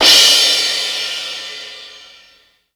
• Crash Sample F# Key 11.wav
Royality free drum crash sample tuned to the F# note. Loudest frequency: 4153Hz
crash-sample-f-sharp-key-11-Hqu.wav